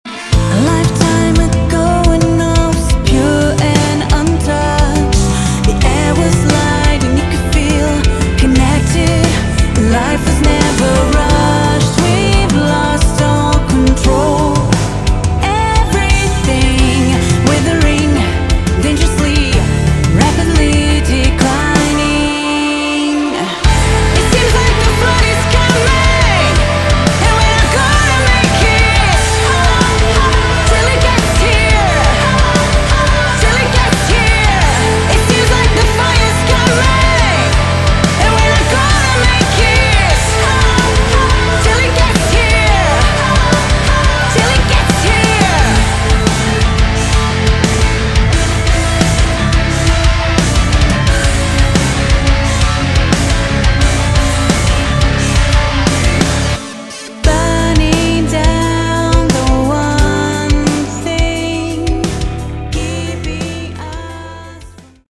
Category: Melodic Metal